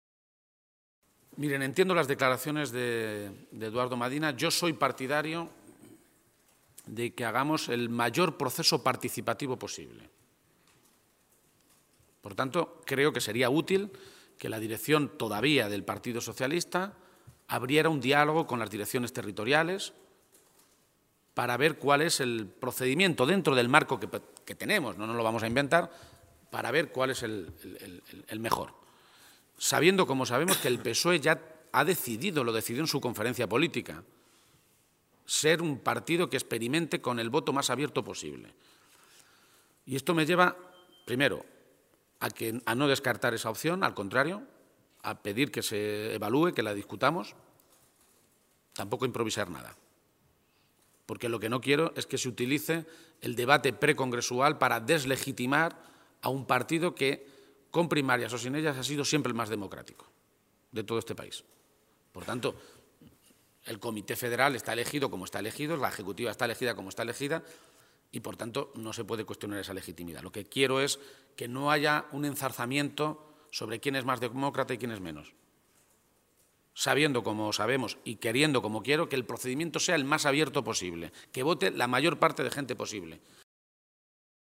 García-Page se pronunciaba de esta manera esta mañana, en Toledo, en una comparecencia ante los medios de comunicación en la que recordaba que él fue el primero, y casi el único, que pidió que se hubieran celebrado las elecciones primarias antes de los pasados comicios europeos.
Cortes de audio de la rueda de prensa